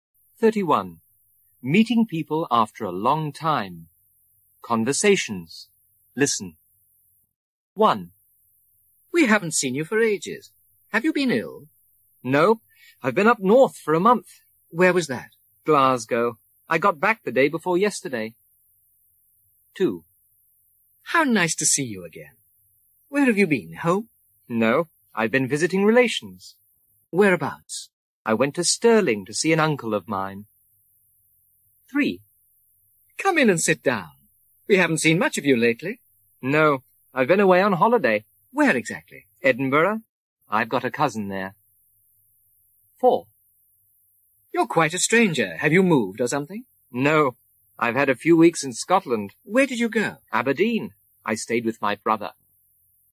برای یادگیری هر چه بهتر این مکالمه، ترجمه فارسی به همراه فایل صوتی مکالمه مورد نظر را برای شما همراهان عزیز وبسایت کاردوآنلاین آماده کرده ایم.